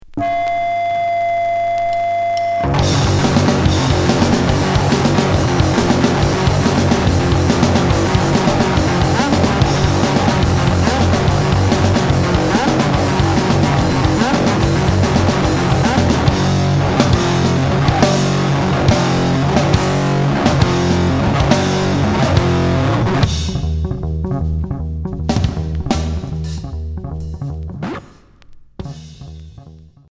Jazz core